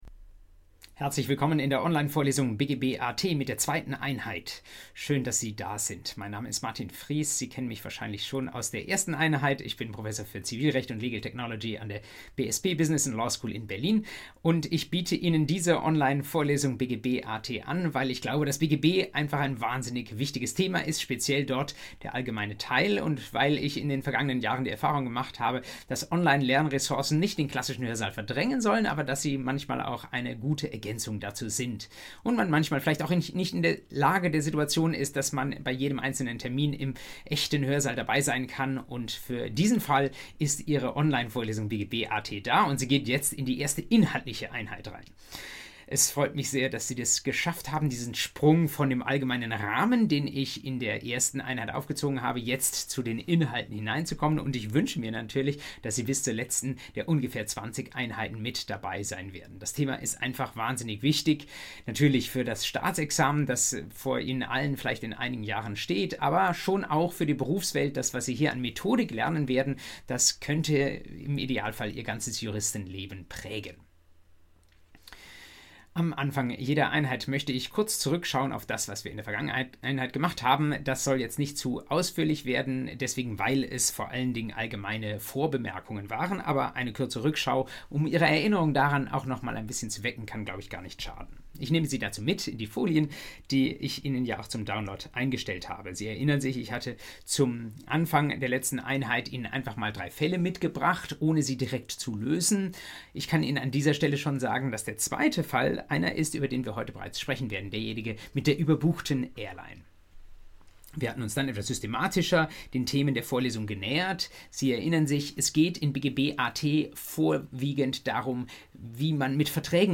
BGB AT Einheit 2: Privatautonomie und Vertragsschluss ~ Vorlesung BGB AT Podcast